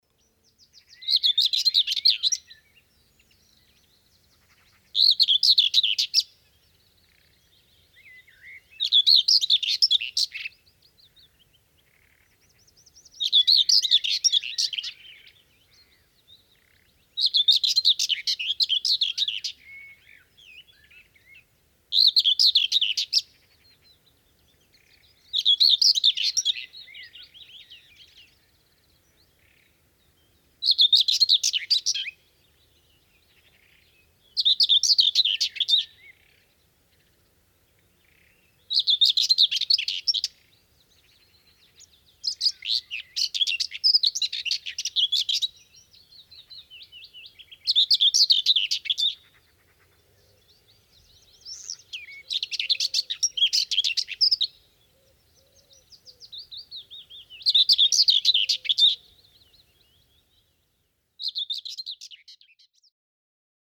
Een begenadigd zanger zou ik de grasmus niet willen noemen, en krasmus zou wat mij betreft dan ook een betere benaming zijn geweest. De grasmus bivakkeert veelal in laag struikgewas, om dan als een duveltje uit een doosje omhoog te schieten en argeloze voorbijgangers de huid vol te schelden.
Grasmus (Sylvia communis)
Locatie: Utrecht Lunetten
69-grasmus-zang.mp3